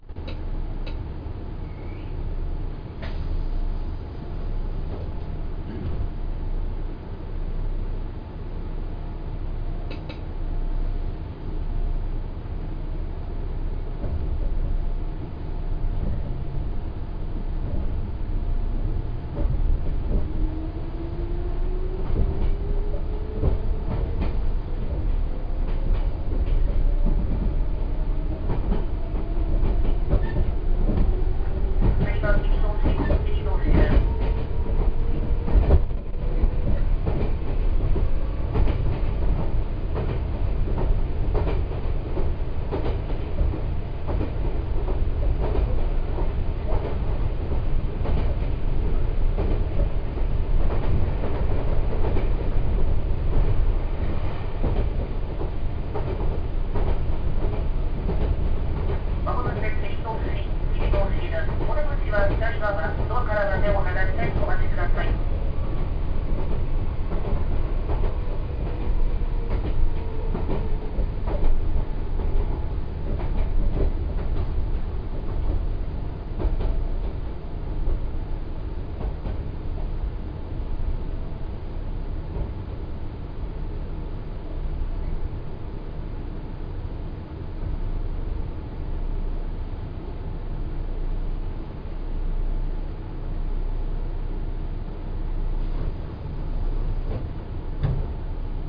・5300系走行音
ただ、随分と渋い音で、あまりチョッパらしくないような気も…。